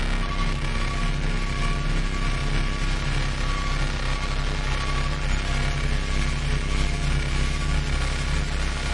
合成器 " 等待合成器 2012年10月15日
描述：不记得我是怎么做的，但请欣赏。它包含了跨越约7个八度的"C"音符。单个音符与演示分开。请听一下各个音符！
标签： 直升机 斩波 摆盘 合成器 5 原因 合成器 托尔 和弦 普罗佩勒黑兹
声道立体声